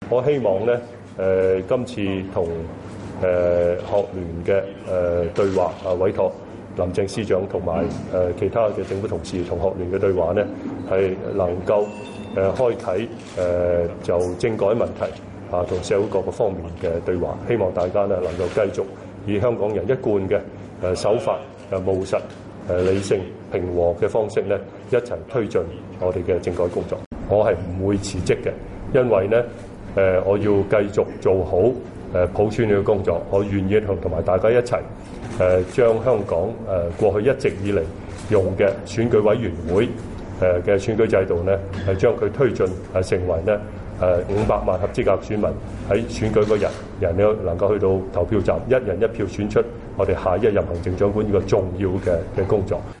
香港特首梁振英在深夜召開記者會, 表示回應香港學聯提出的對話要求, 並委派政務司司長林鄭月娥與香港學聯盡快展開對話。